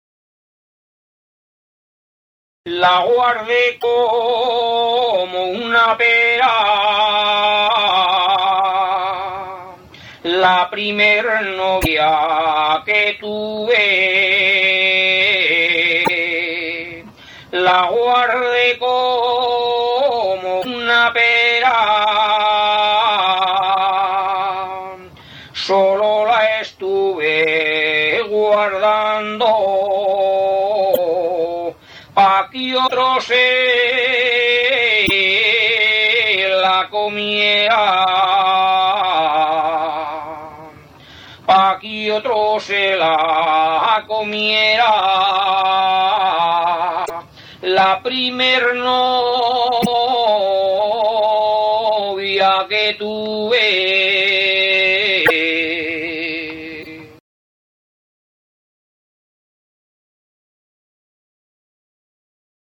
JOTAS